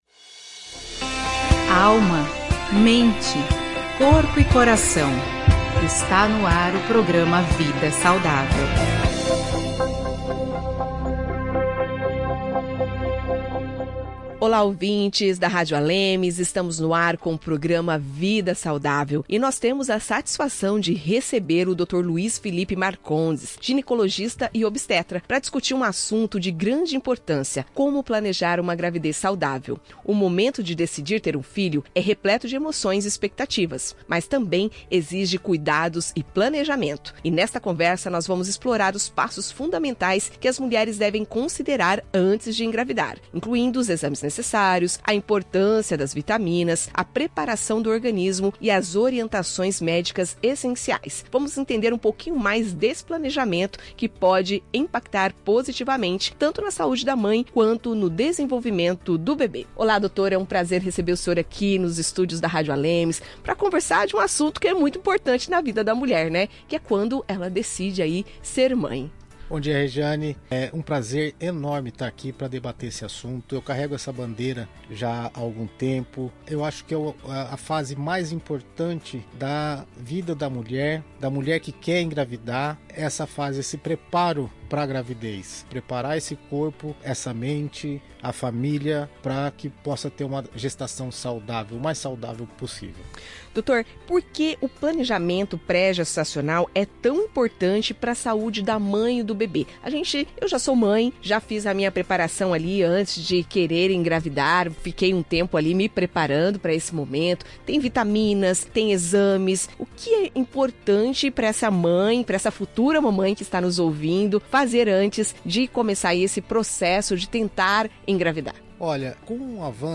Na entrevista, o médico explica quais exames devem ser feitos, a preparação do organismo e os cuidados que ajudam a garantir mais saúde para a mãe e para o bebê.